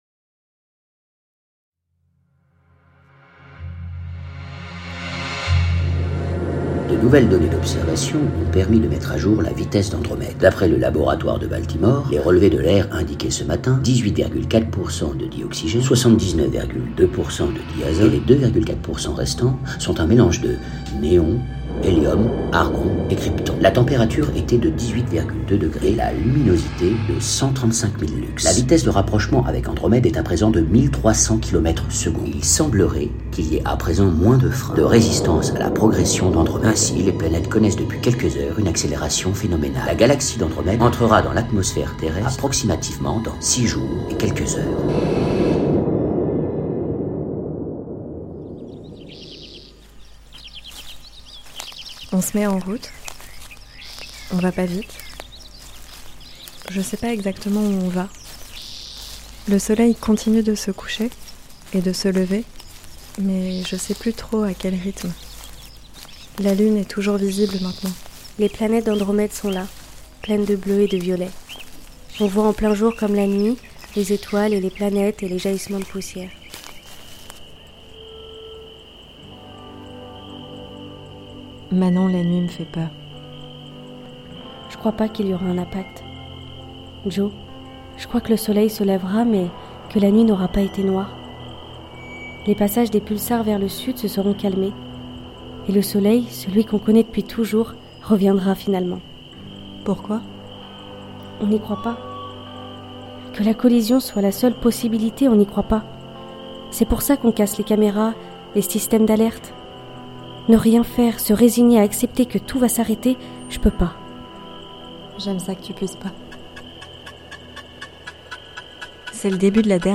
La création sonore évoque le réel de la nature et le surnaturel de la catastrophe à partir des sons du vivant issus d’enregistrements d’audio-naturalistes.